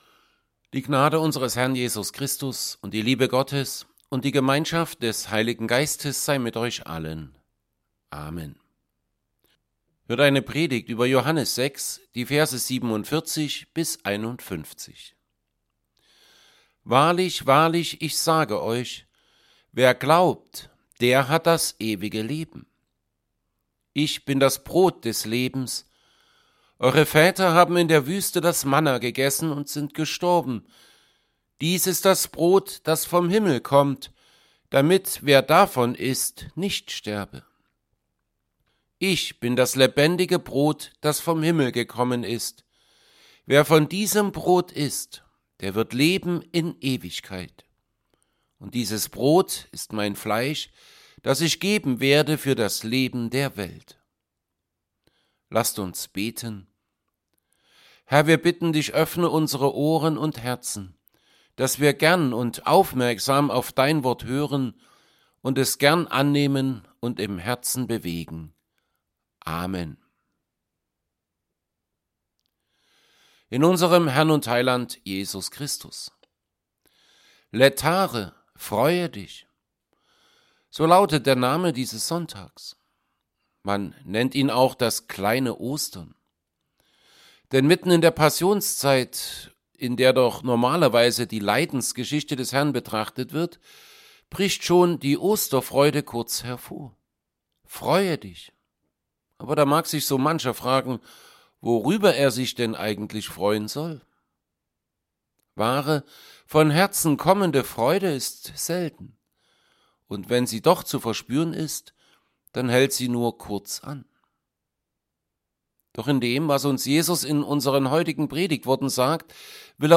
Serie: Evangelienpredigten Passage: John 6:47-51 Gottesdienst
Predigt_zu_Johannes_6_47b51.mp3